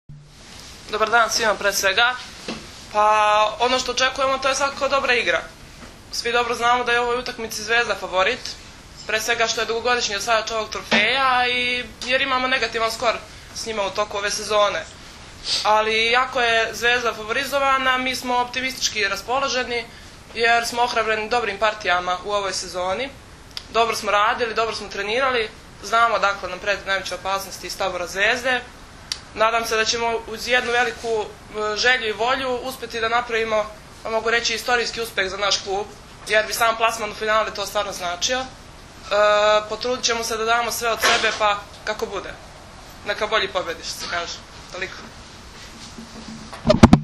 U prostorijama Odbojkaškog saveza Srbije danas je održana konferencija za novinare povodom Finalnog turnira 48. Kupa Srbije u konkurenciji odbojkašica, koji će se u subotu i nedelju odigrati u dvorani “Park” u Staroj Pazovi.